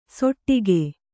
♪ soṭṭage